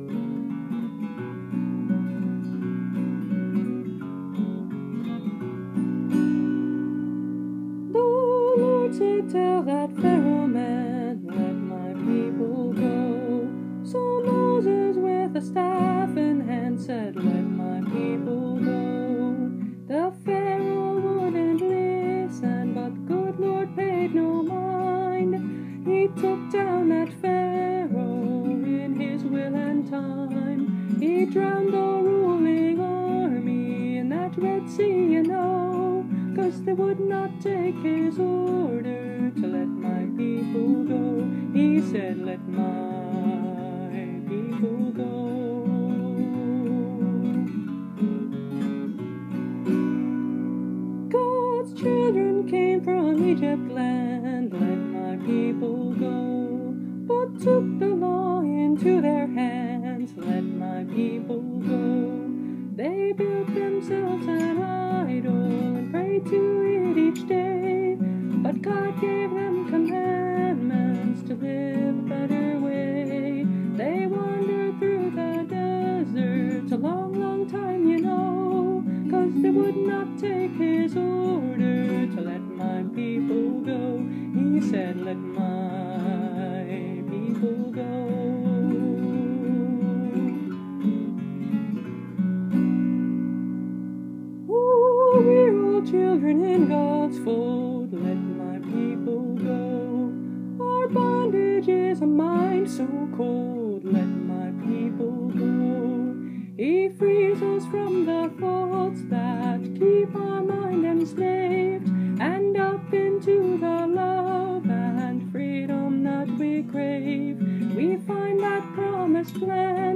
Instrument: Tempo – Seagull excursion folk acoustic guitar
(Capo 2)